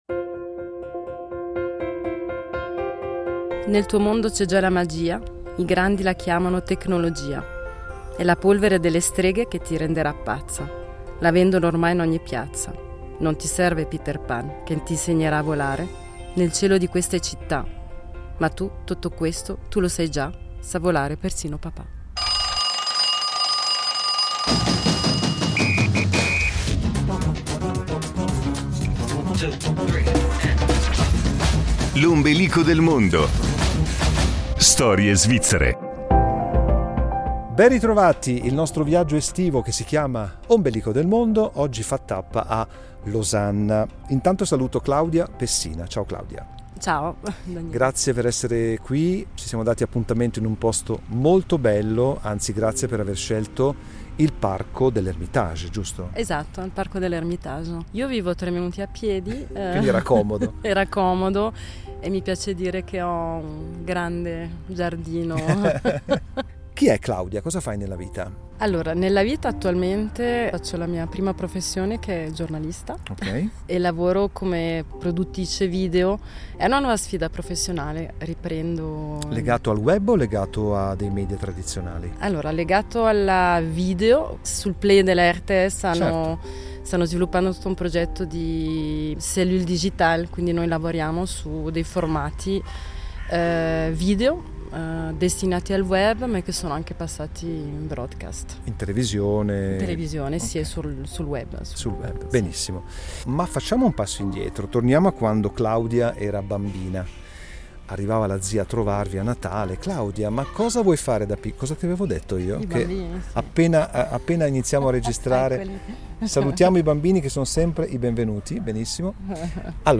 Musicalmente ha scelto un brano di Eugenio Finardi che invita alla celebrazione, al gioco e alla magia.